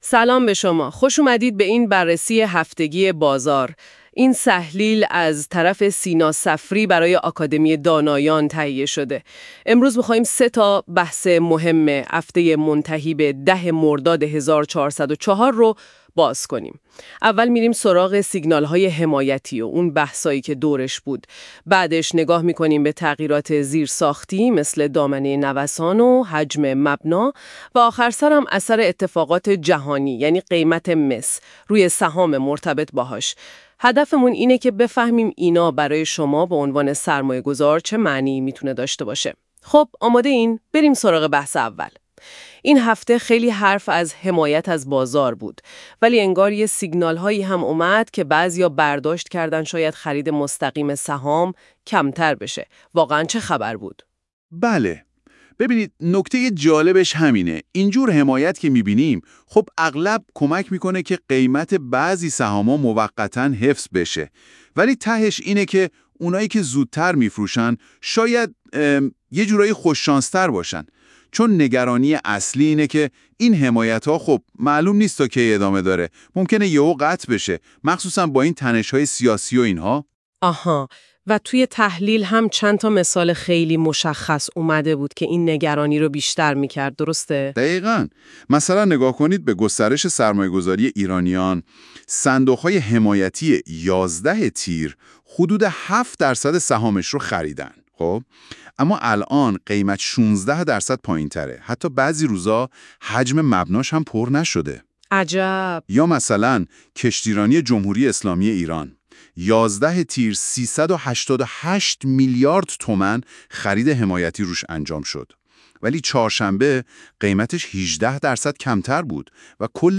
پادکست تحلیل آکادمی دانایان با امکانات هوش مصنوعی